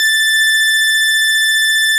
snes_synth_081.wav